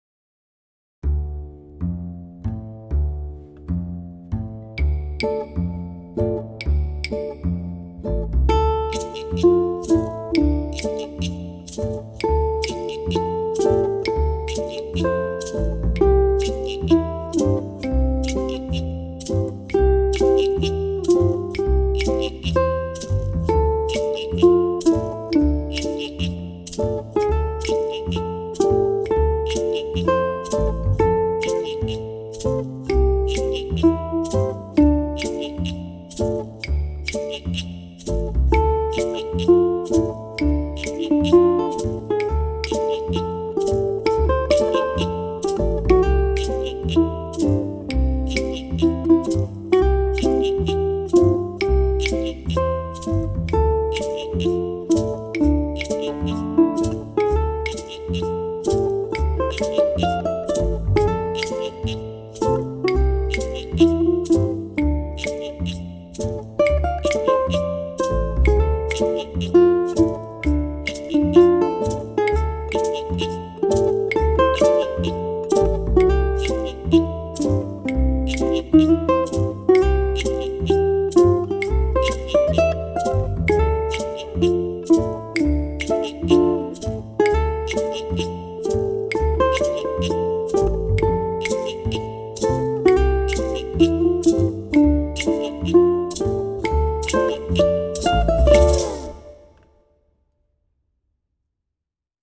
Sinner Man, also known as Oppressor Man and Downpressor Man, is a traditional African American spiritual song.
Perform Sinner Man with a moderate or faster reggae (Jamaican) tempo and feel. In the track below, I play the repeated section three times (rather than two) and add embellishments to the melody during the second and third repeats.
It's a simple introduction consisting of the first 4 bars of the song, i.e., four measures of Dmin chord.
ʻukulele
Reggae strum, chucking